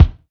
Kick (69).wav